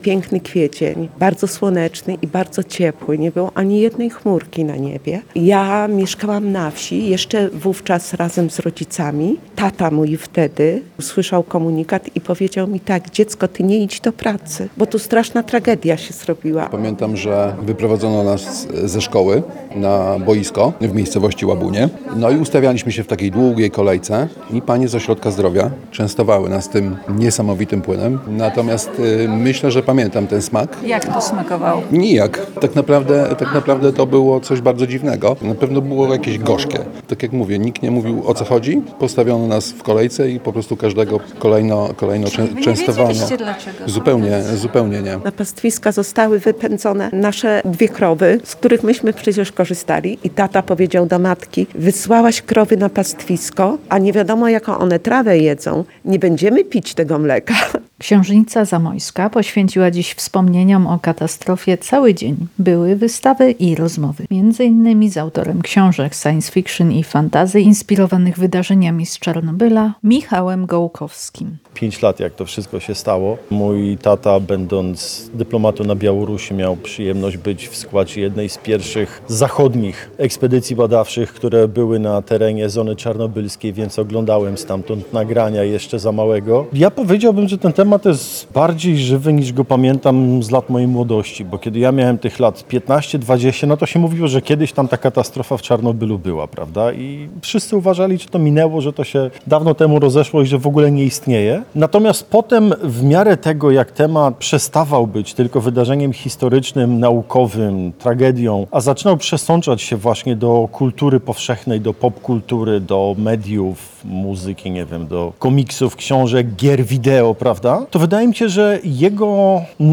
Wiele osób pamięta, jaka była wtedy pogoda oraz jak smakował płyn Lugola. Wspomnieniami dzielili się z Radiem Lublin mieszkańcy Zamościa.